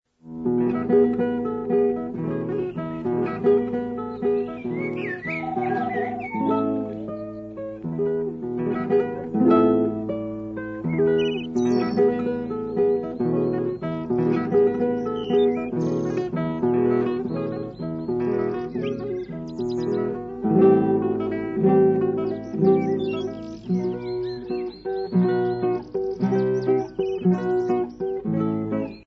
Ηχητική μπάντα παράστασης
Δείγματα από τη μουσική της παράστασης
sound 29'', track 02, μουσική (κιθάρα)
sound 29'', track 02, ηχητικό εφέ (κελάιδισμα πουλιών)